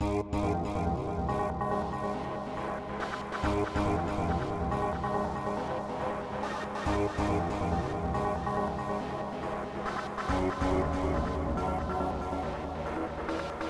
标签： 140 bpm Psychedelic Loops Pad Loops 2.31 MB wav Key : Unknown
声道立体声